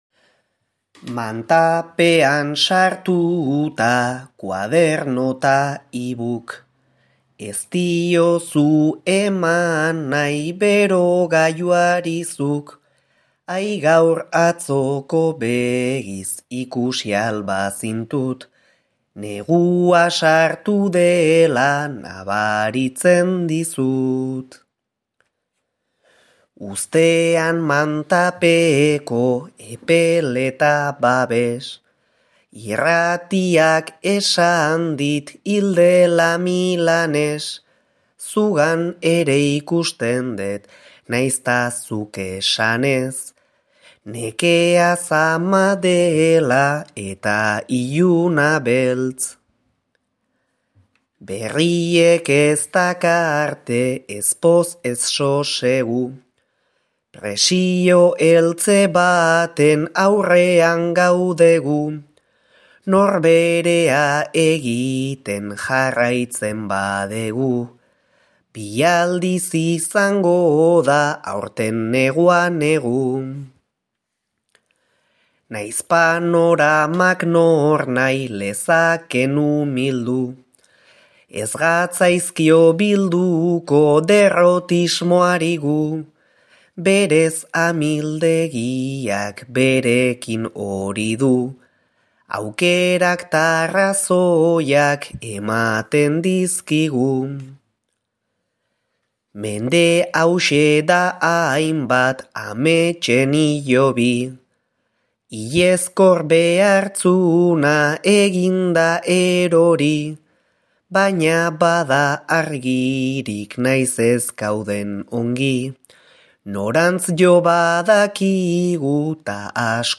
'Bi aldiz negu' bertso sortarekin.